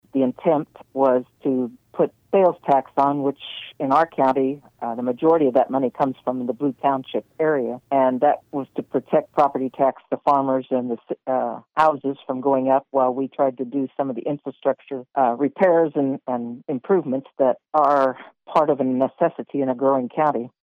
Commissioner Dee McKee, in an interview Wednesday with KMAN noted she was disappointed the sales tax question failed.